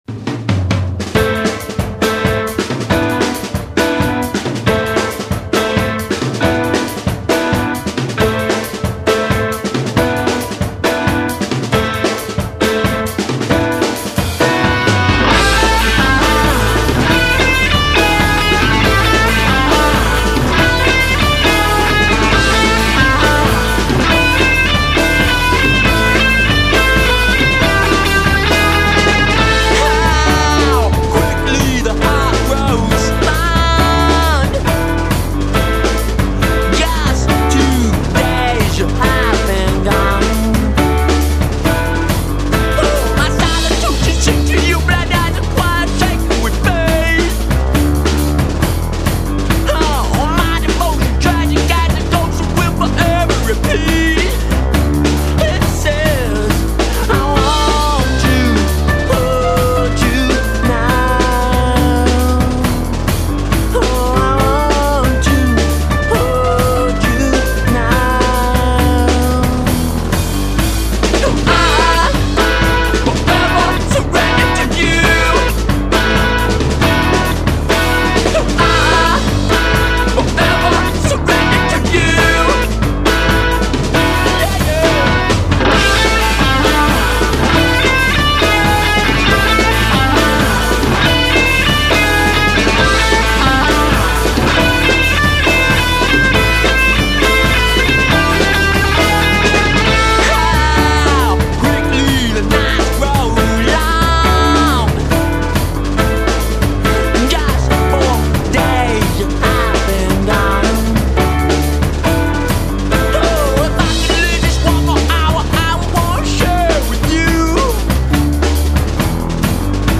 vocals, guitar
organ
rollicking, solid, charismatic, and confident trio
exciting record filled with yearning and longing